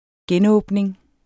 Udtale [ ˈgεnˌɔˀbneŋ ]